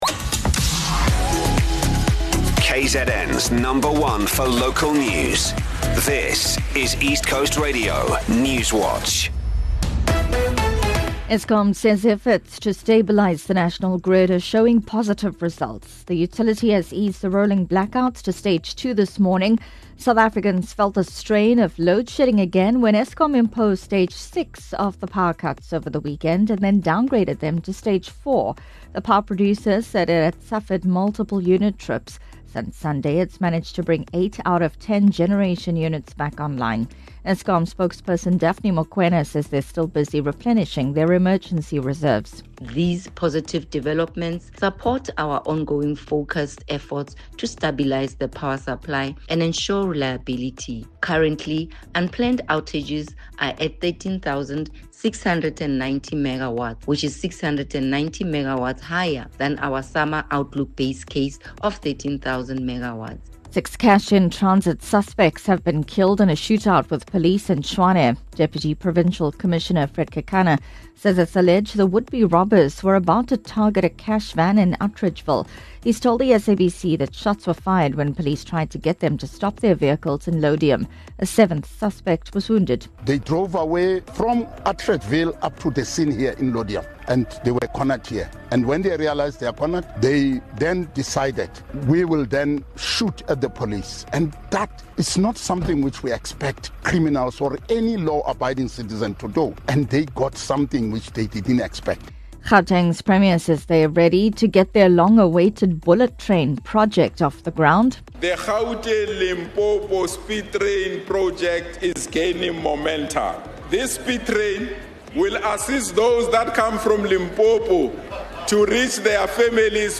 Here’s your latest ECR Newswatch bulletin from the team at East Coast Radio.